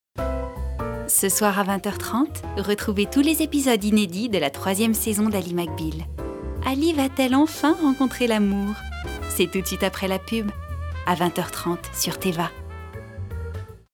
Extraits voix